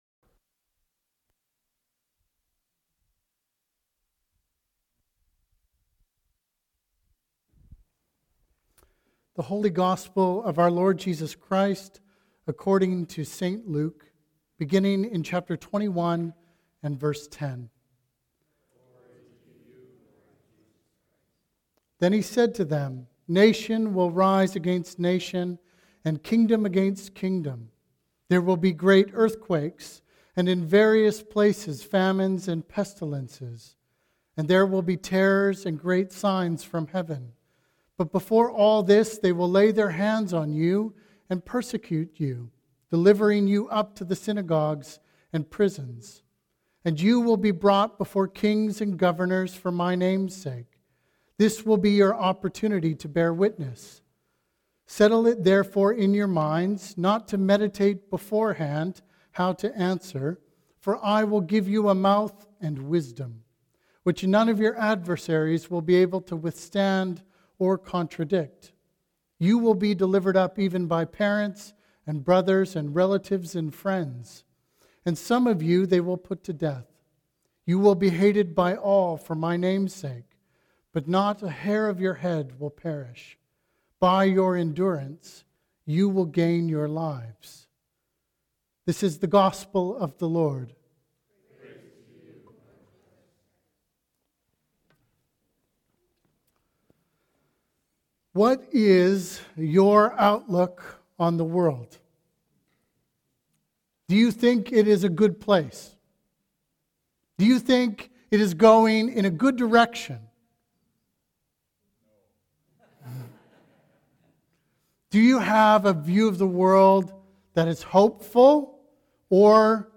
Sermons | Christ's Church Oceanside